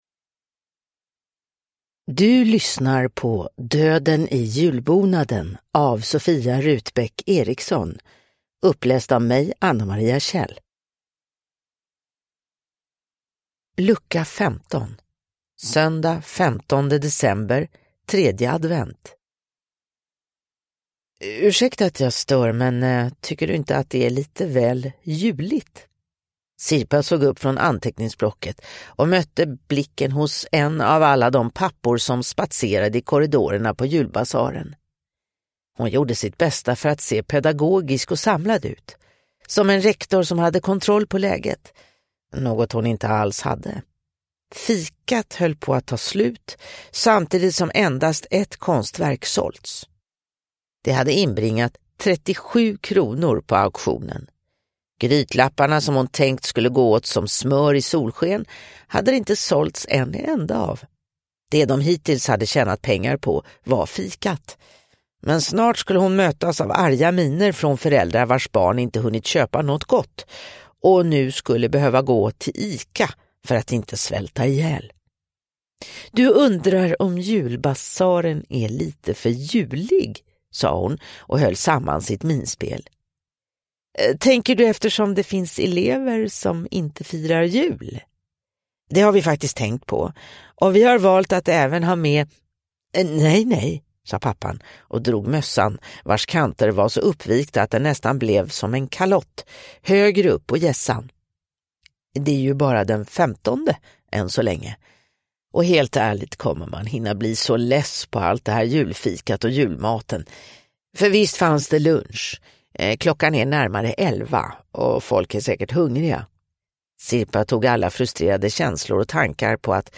Döden i julbonaden: Lucka 15 – Ljudbok